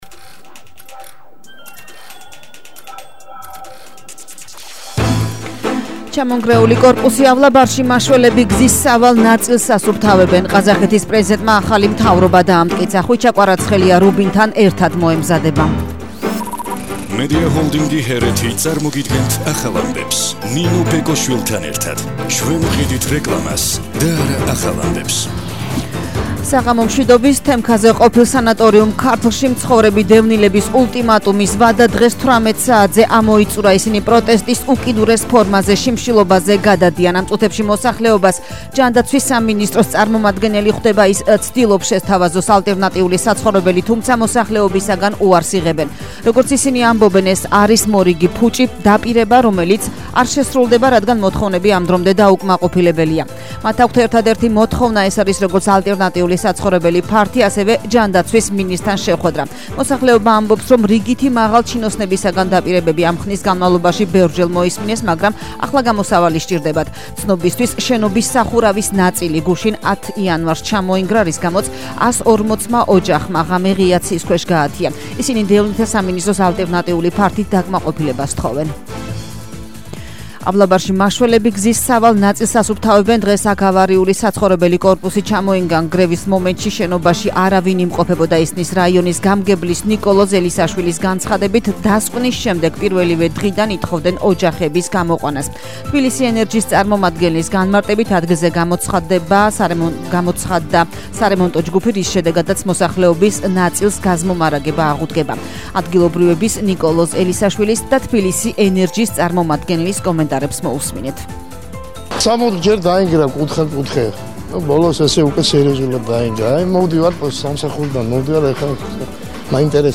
ახალი ამბები 19:00 საათზე – 11/01/22